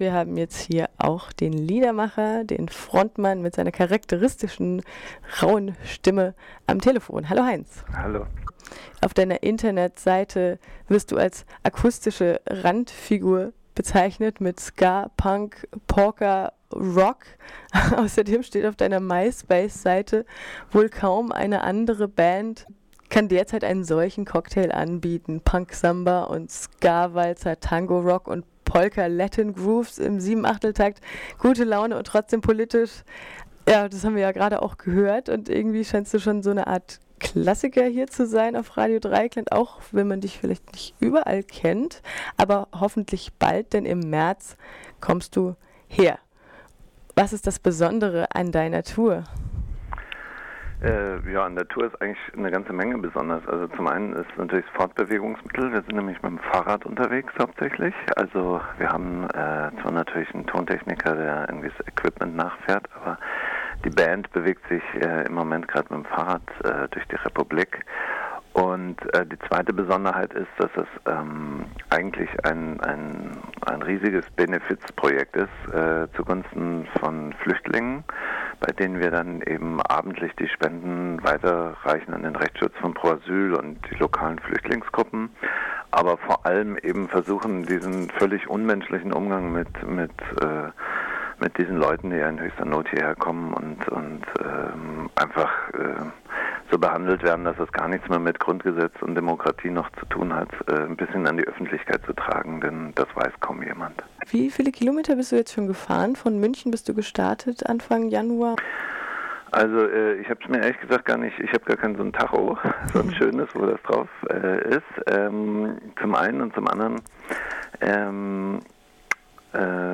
Weltverbesserer? Sozialromantiker? - Ein Interview